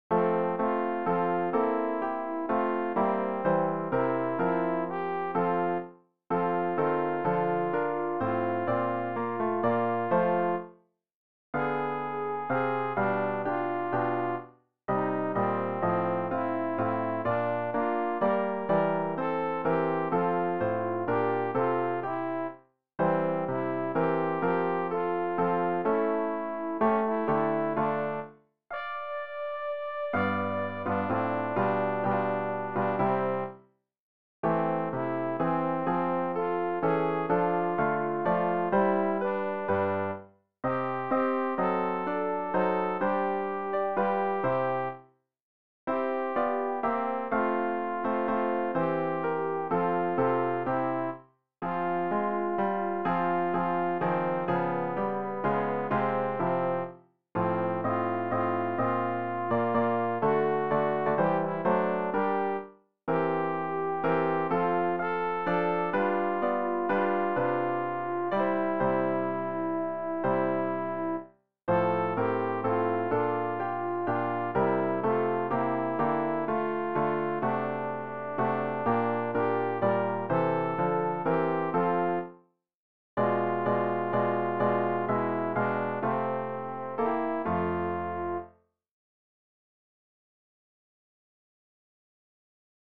alt-haydn-stella-coeli.mp3